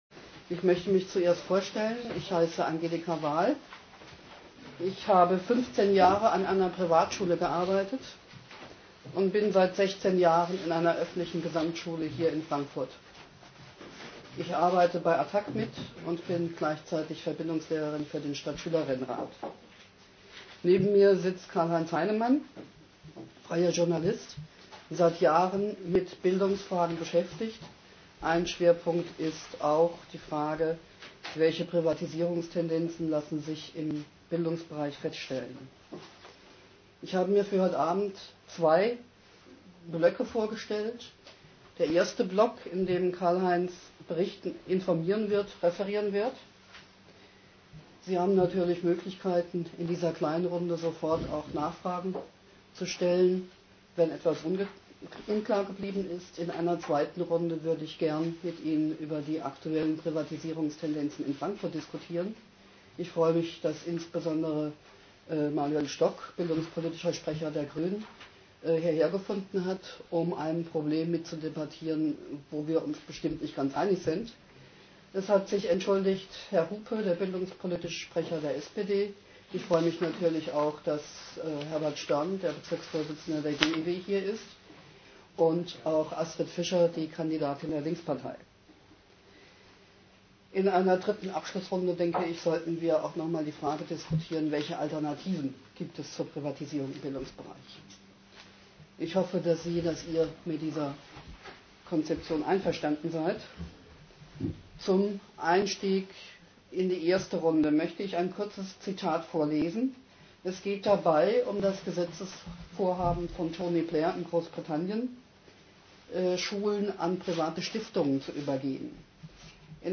Frankfurt, 6. März´06, Info-Veranstaltung
Schüler + Antwort/